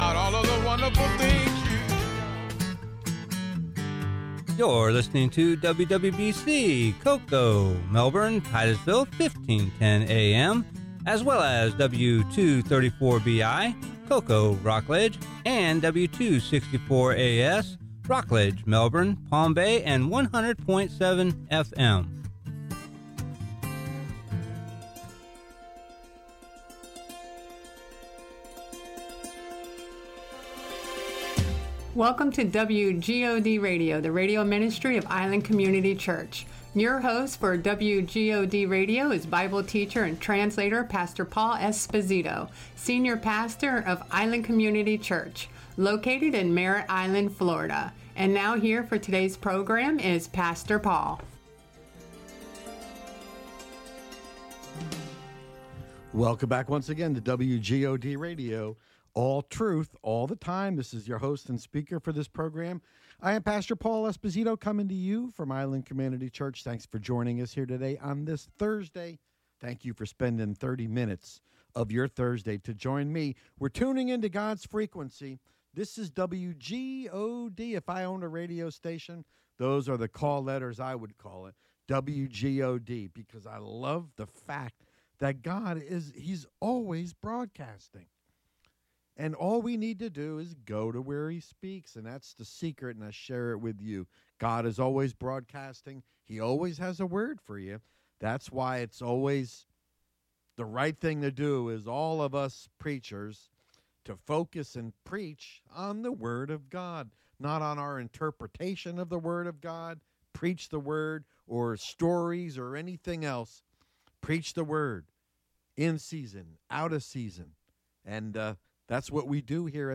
Sermon: "Jesus and Adam" Part 2 Romans Ch.